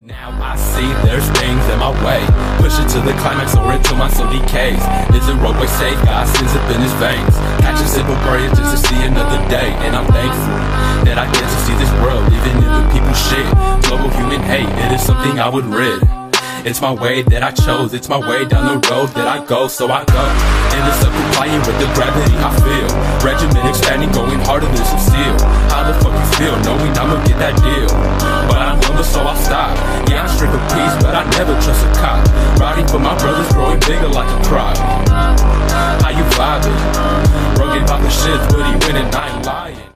Хип-хоп
Рэп